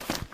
STEPS Dirt, Run 12.wav